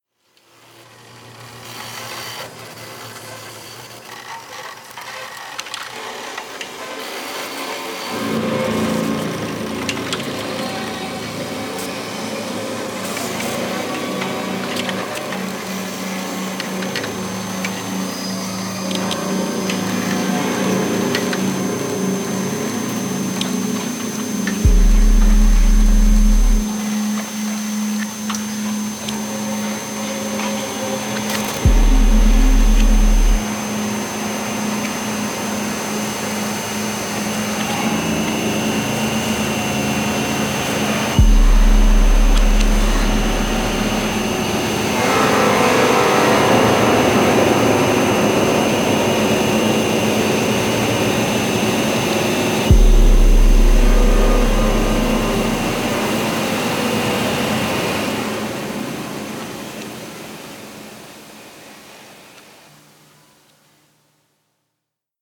prepared violin, prepared piano, live 2-channel sound